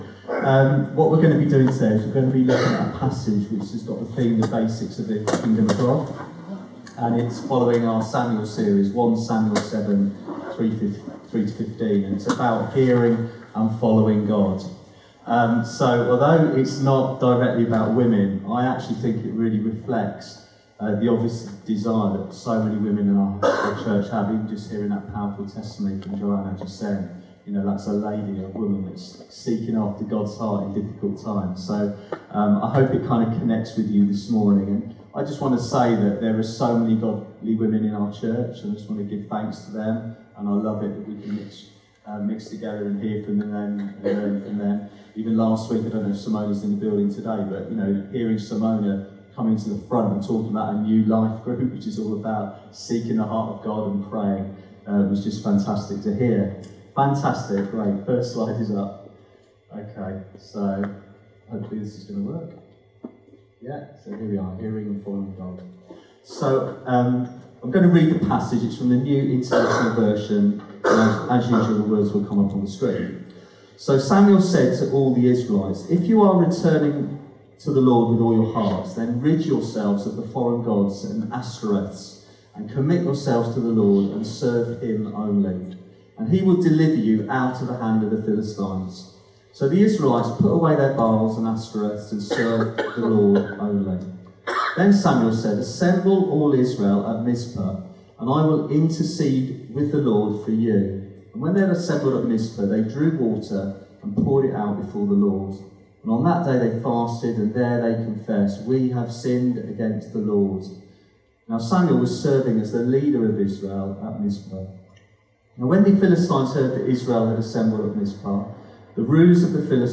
Download The basics of God’s Kingdom | Sermons at Trinity Church